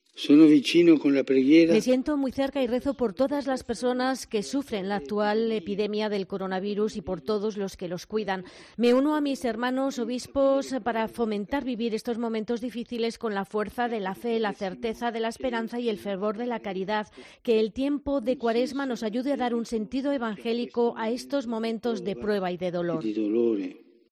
El Papa ante la crisis del coronavirus en el rezo del Ángelus
“Es un poco extraño esta oración del Ángelus de hoy con el Papa ‘enjaulado’ en la Biblioteca, pero yo los veo, les soy cercano”, ha asegurado el Pontífice desde la Biblioteca del Palacio Apostólico del Vaticano en transmisión televisiva en vivo.